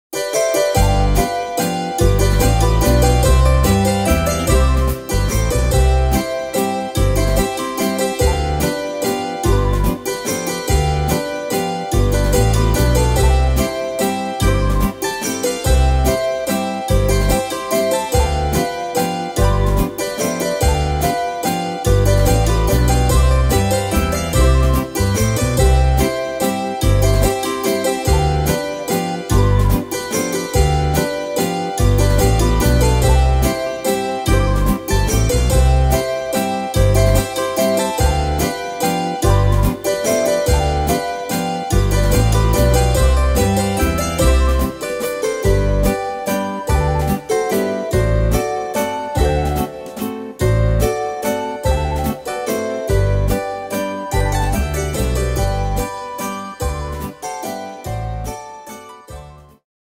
Tempo: 145 / Tonart: C-Dur